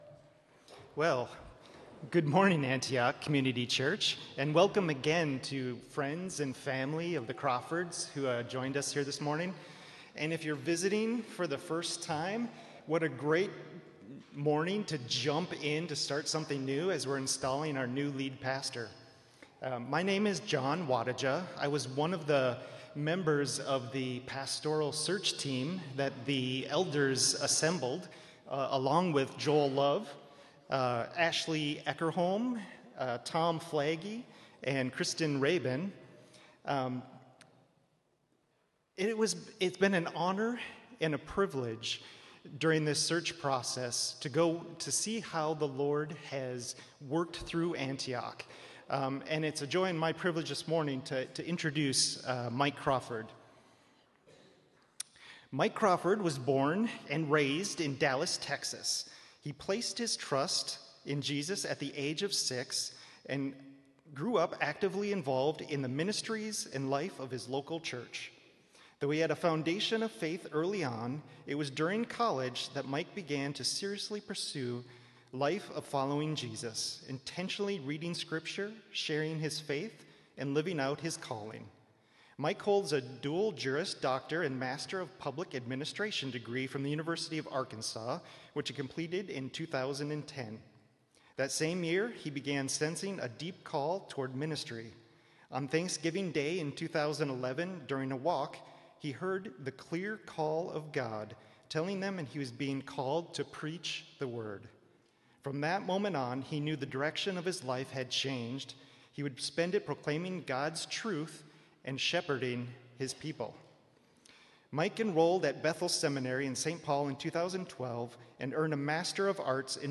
Pastoral Installation Service